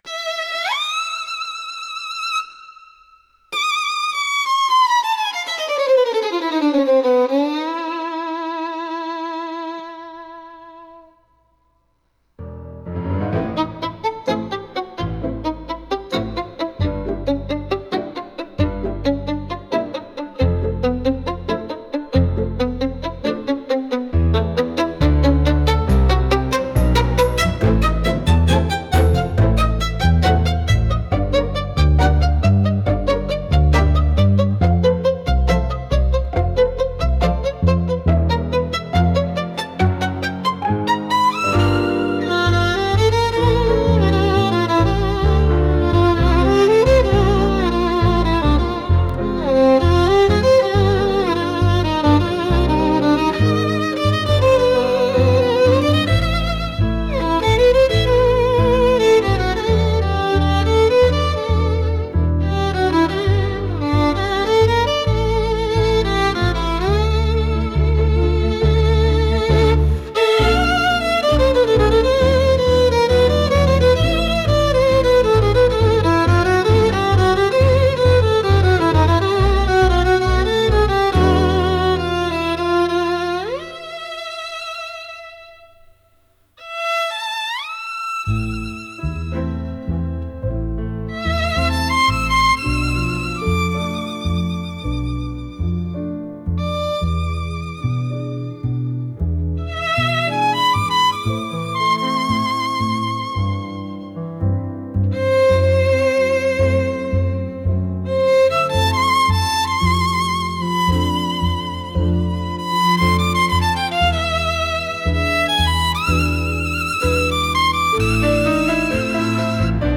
Instrumental / 歌なし
深紅の光が差し込むような情熱と、大人の魅力が漂うタンゴナンバー。